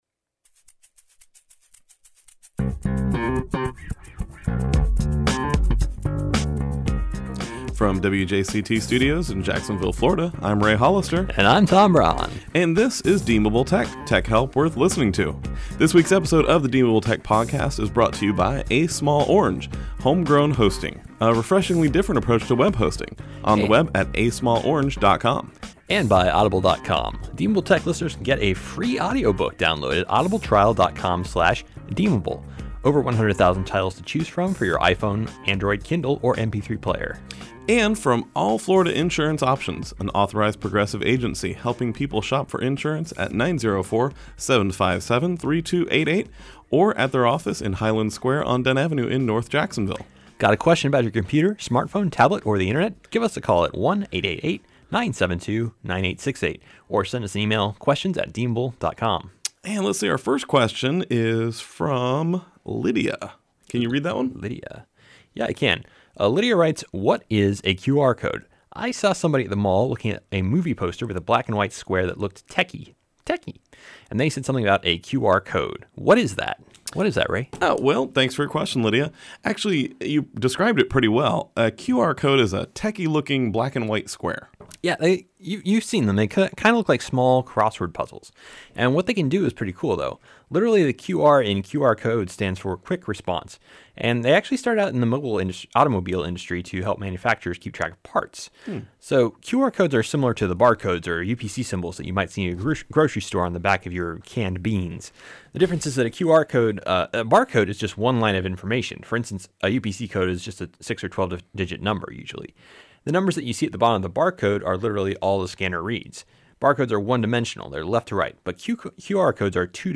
From WJCT studios in Jacksonville, Florida